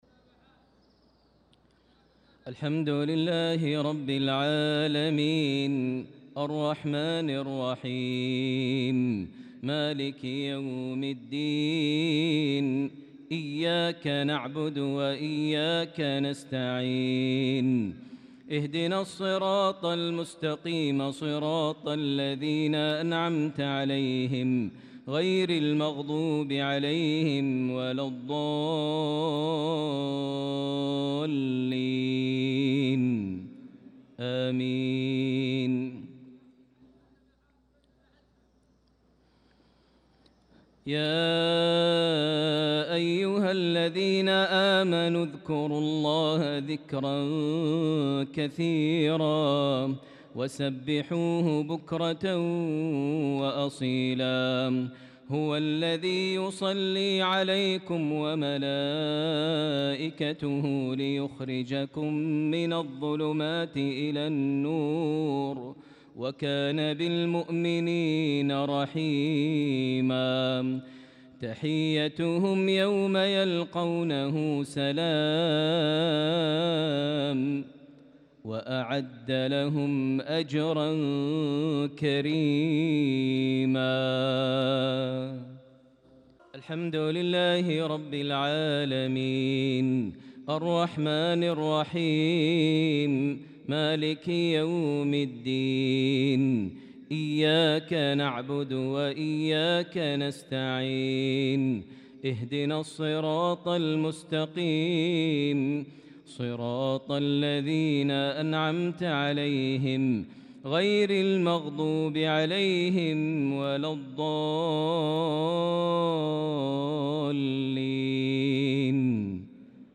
صلاة المغرب للقارئ ماهر المعيقلي 9 شوال 1445 هـ
تِلَاوَات الْحَرَمَيْن .